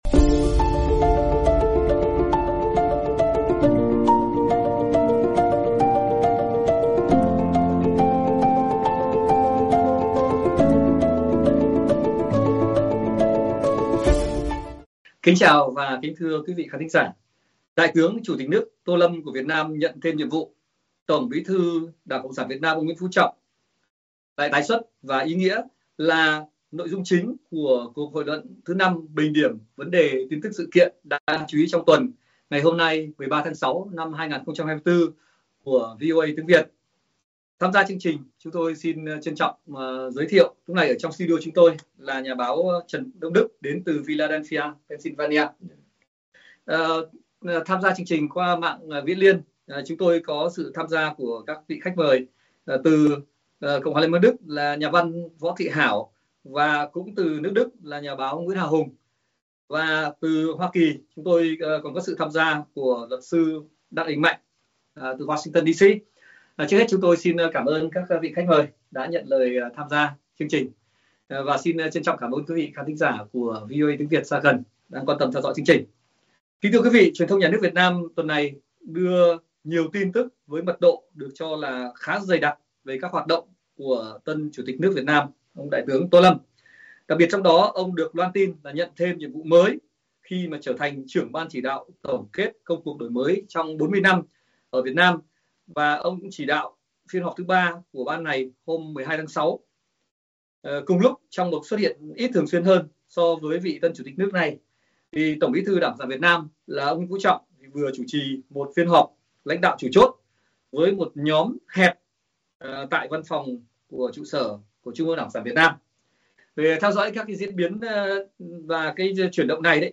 Các khách mời là nhà phân tích, quan sát thời sự chính trị - xã hội Việt Nam từ Hoa Kỳ và hải ngoại thảo luận, phân tích chuyển động chính trị Việt Nam qua các động thái mới được trình bày trước công luận của tân Chủ tịch nước Tô Lâm và TBT Nguyễn Phú Trọng, bên cạnh một số sự kiện, chuyển động thời sự đáng chú ý khác trong tuần.